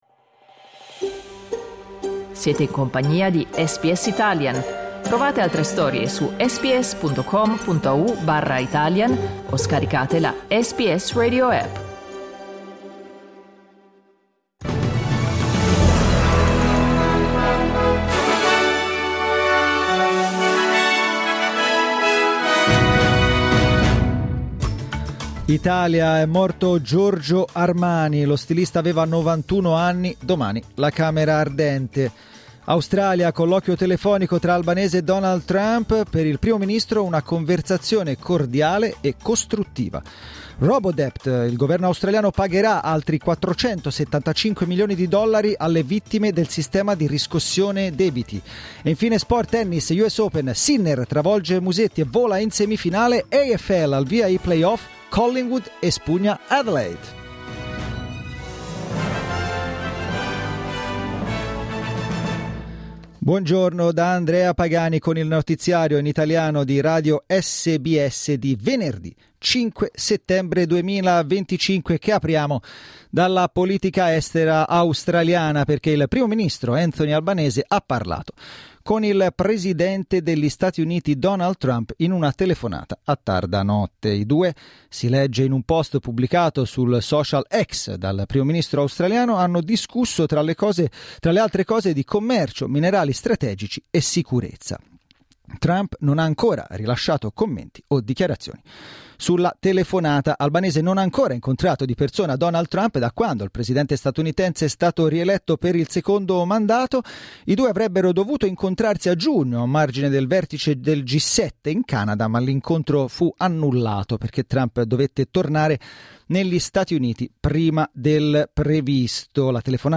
Giornale radio venerdì 5 settembre 2025
Il notiziario di SBS in italiano.